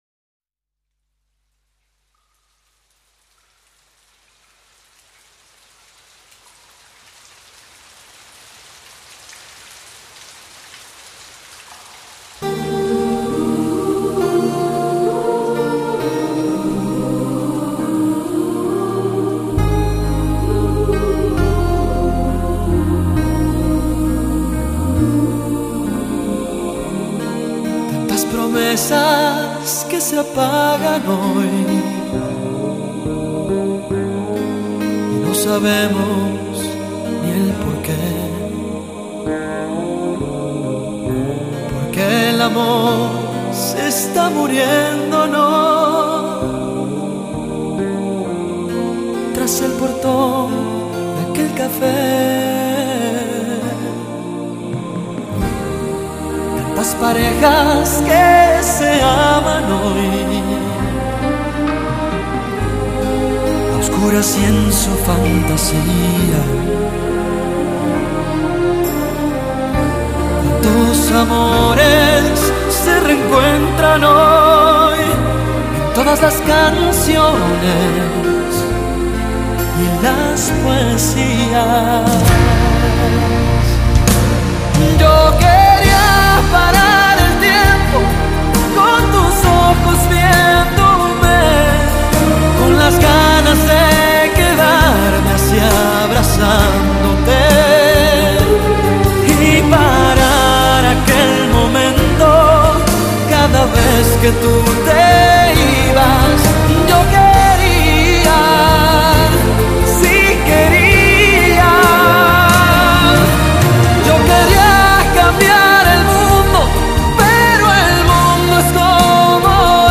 把热闹、动感的拉丁摇滚曲风演绎得酣畅淋漓。
他磁性的嗓音不管表现忧郁抑或欣然都能信手拈来，使专辑呈现出舒缓的浪漫诗篇的景象。